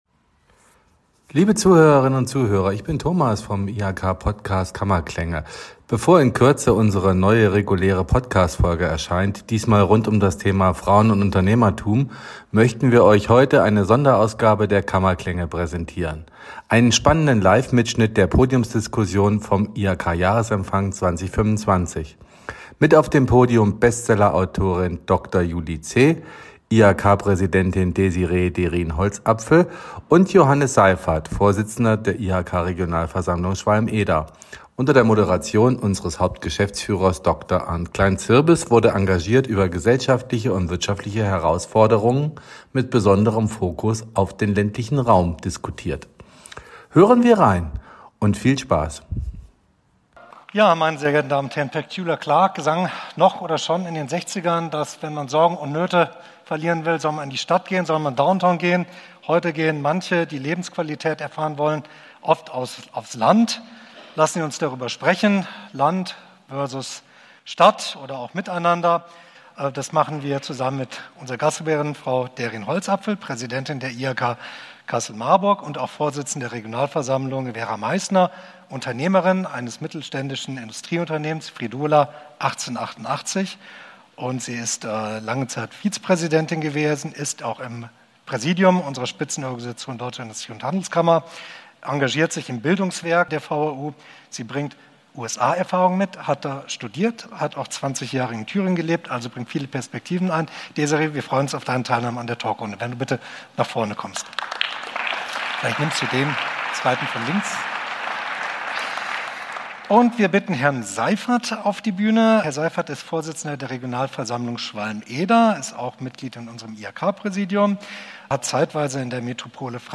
Sonderfolge: Podiumsdiskussion vom IHK-Jahresempfang 2025 ~ KammerKlänge - Der Podcast der IHK Kassel-Marburg Podcast
Am 2. April fand der diesjährige IHK-Jahresempfang statt.